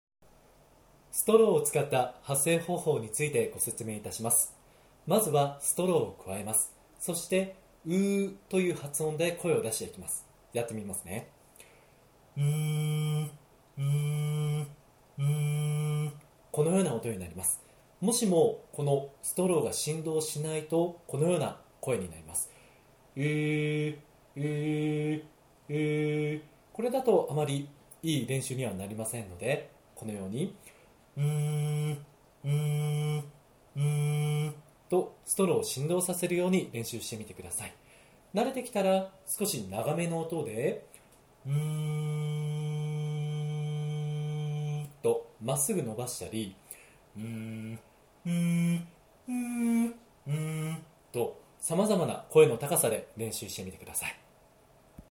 声帯トレーニング②ストローを使って声を出す
ストローを口にくわえて、「う〜」と声を出してみましょう。
声の大きさと高さは、普段話す時と同じくらいで大丈夫です。